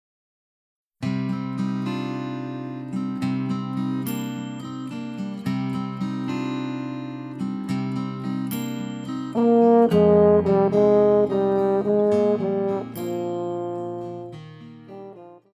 Pop
French Horn
Band
Instrumental
World Music,Fusion
Only backing